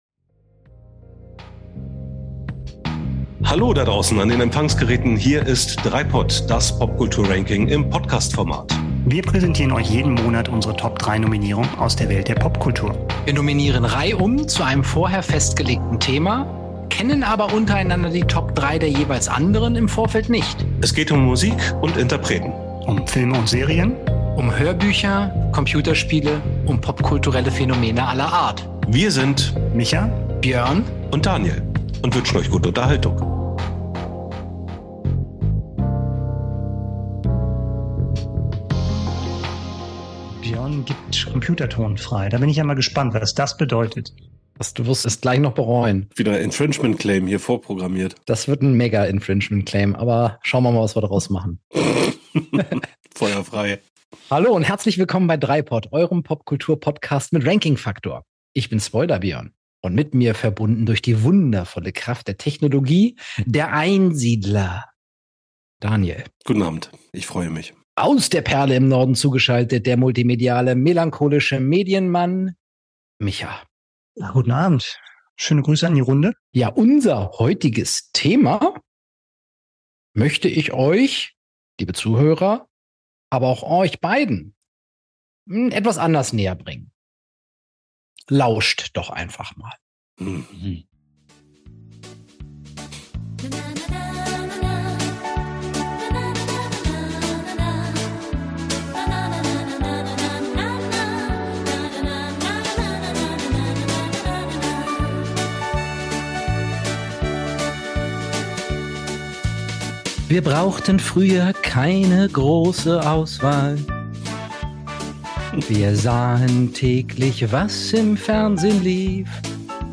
Als wäre das nicht genug, gibt einer der DreiPötte sogar sein Gesangsdebüt.